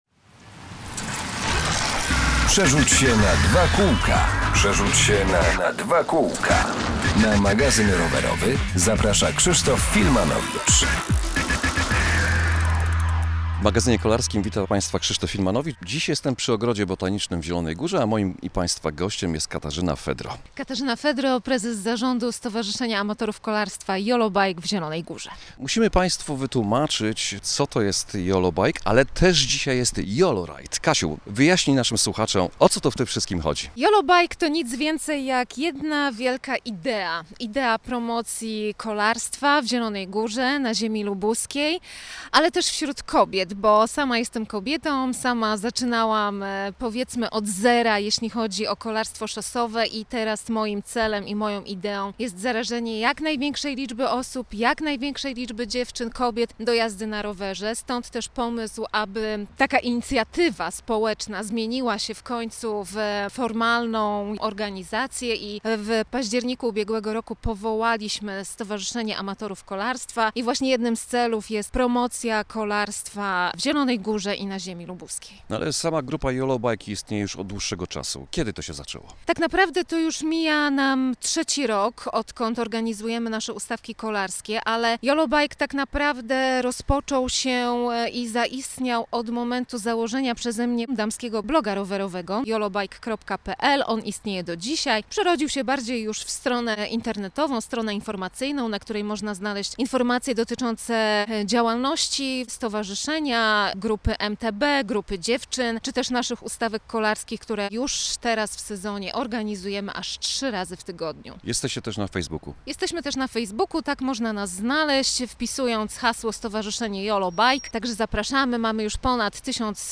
Z mikrofonem Radia Zielona Góra zagościliśmy na wtorkowym YOLORIDE, czyli rajdzie dziewczyn na rowerach szosowych.